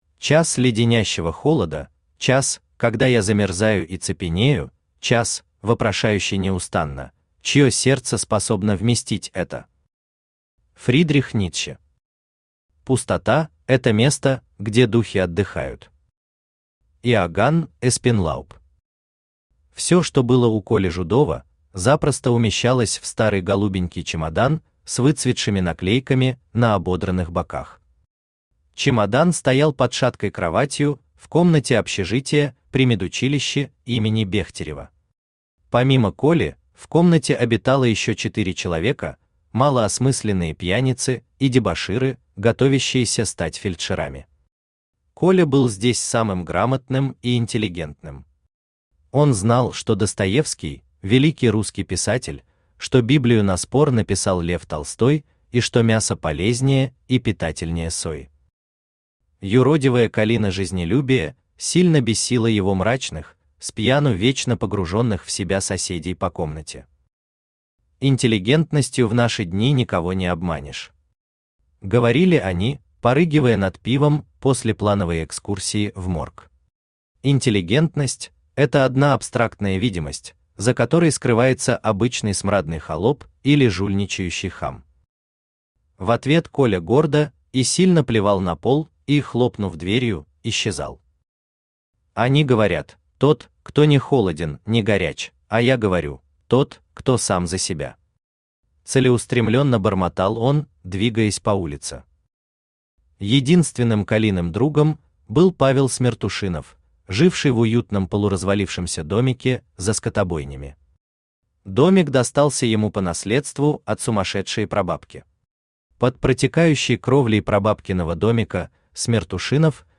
Аудиокнига Потребитель | Библиотека аудиокниг
Aудиокнига Потребитель Автор Владислав Георгиевич Тихонов Читает аудиокнигу Авточтец ЛитРес.